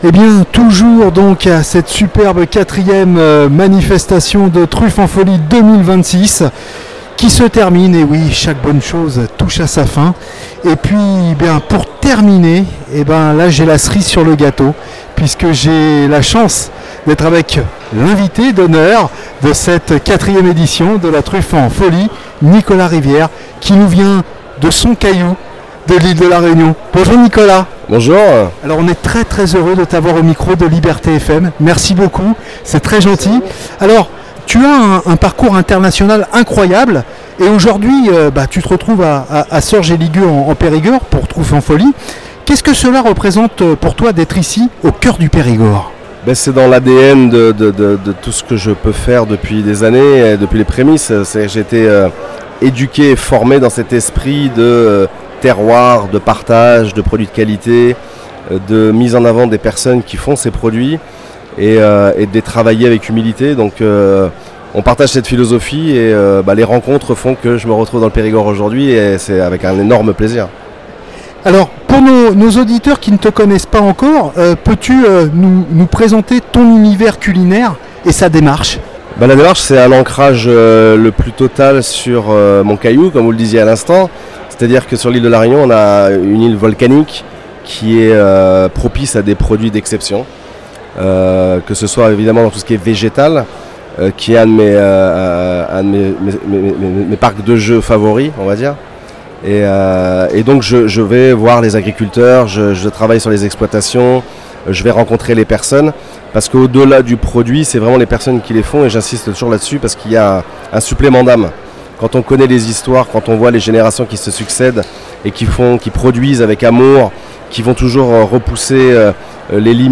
nous avons eu la chance de recevoir à notre micro